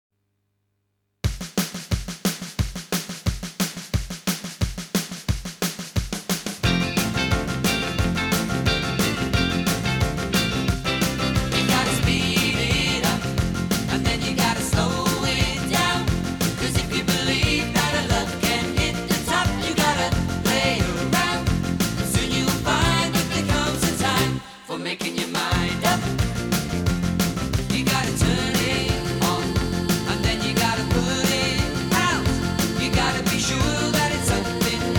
Жанр: Поп музыка / Рок / R&B / Соул / Диско